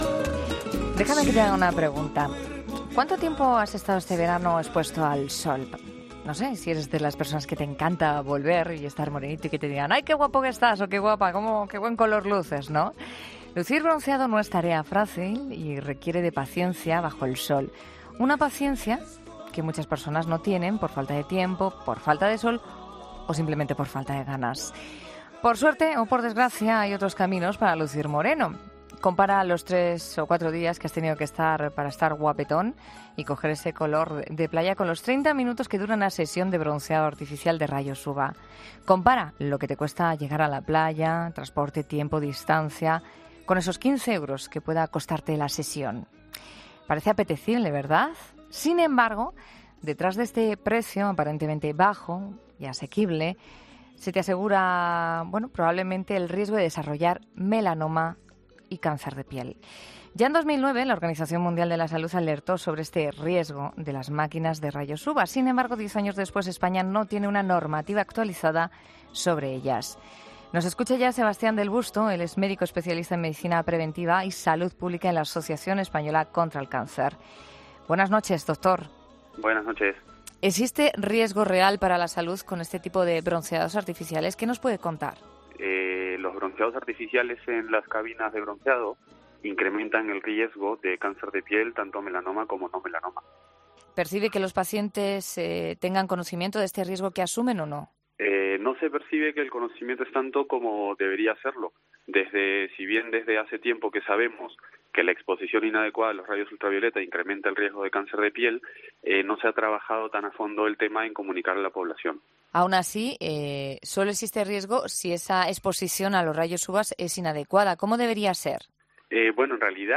ha estado en 'La Noche de COPE' para explicar los riesgos